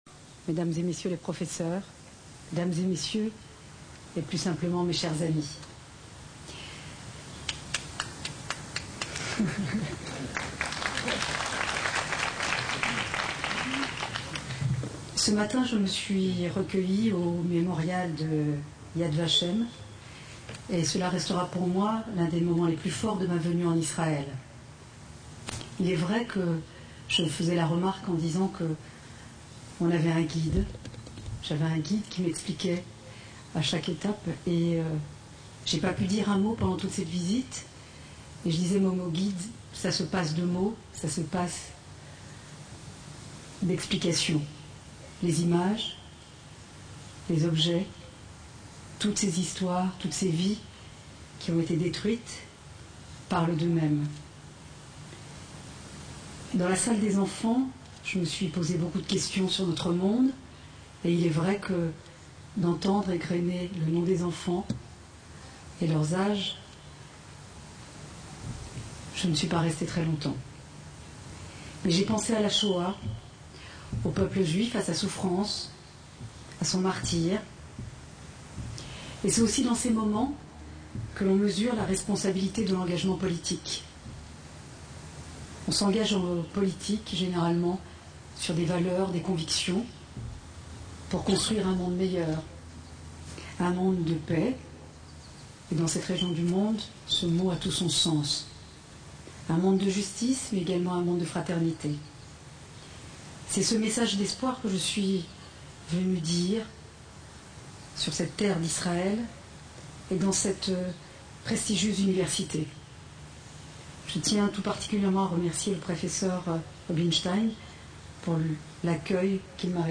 Rachida Dati en Israël